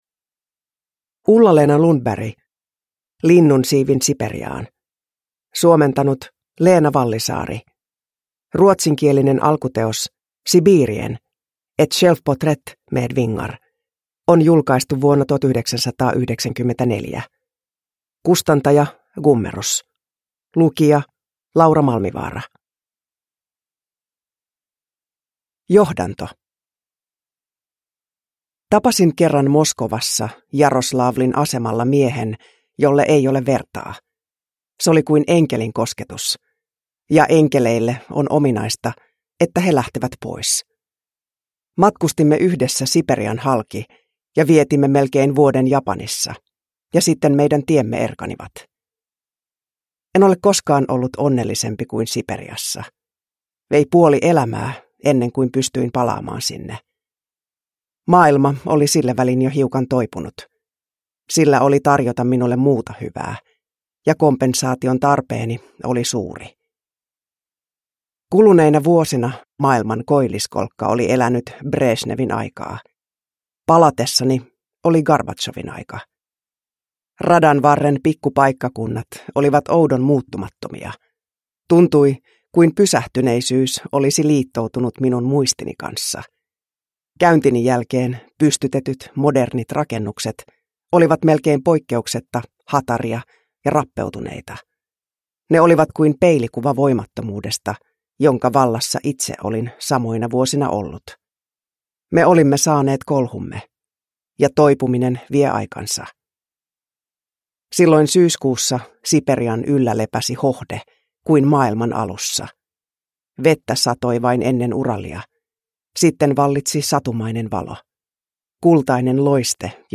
Linnunsiivin Siperiaan – Ljudbok
Uppläsare: Laura Malmivaara